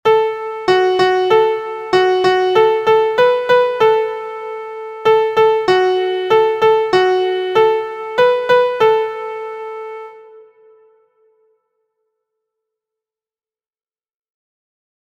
• Origin: England – Nursery Rhyme
• Key: D Major
• Time: 2/4
• Form: ABaB